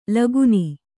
♪ laguni